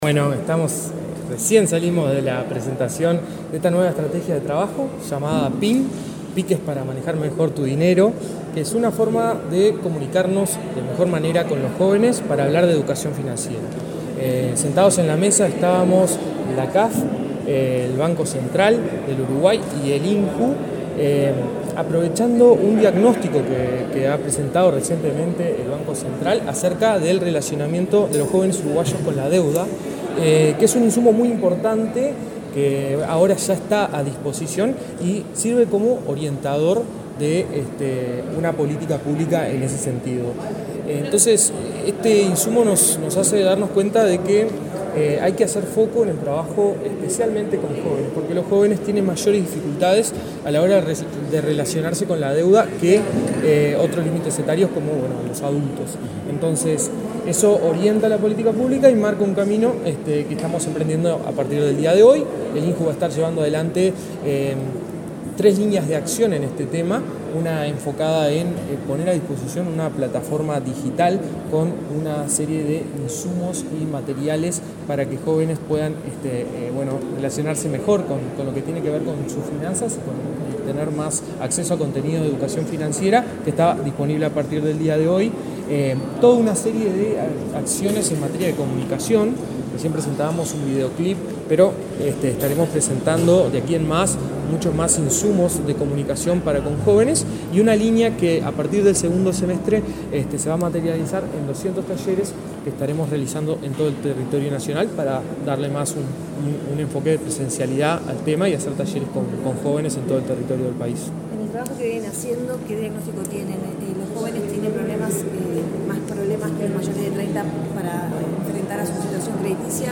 Declaraciones del director del INJU, Aparicio Saravia
Declaraciones del director del INJU, Aparicio Saravia 27/02/2024 Compartir Facebook Twitter Copiar enlace WhatsApp LinkedIn El director del Instituto Nacional de la Juventud (INJU), Aparicio Saravia, dialogó con la prensa en Torre Ejecutiva, luego de participar de la presentación de una línea de trabajo para promocionar la educación financiera para jóvenes de todo el país.